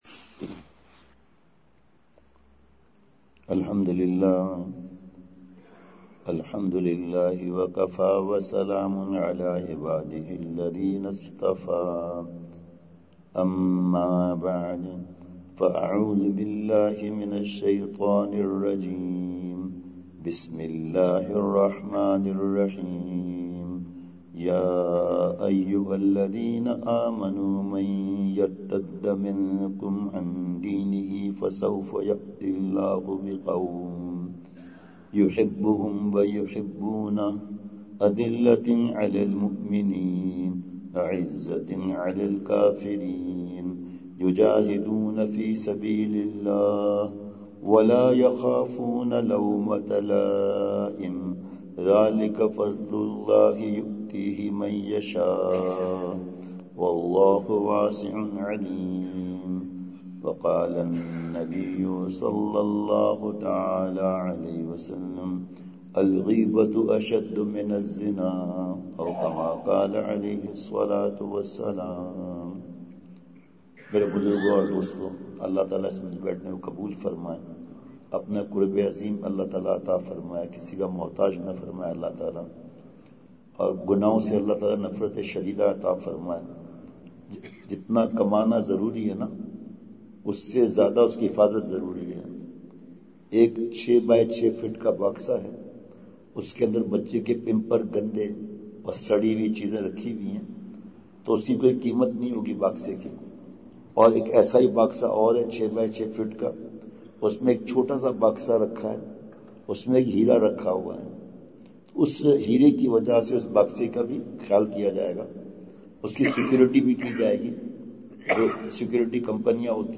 *بمقام۔مسجد امیر حمزہ ملٹی گارڈن اسلام آباد*
*نمبر(9):بیان*
یہ بیان بعد فجر ہوا۔۔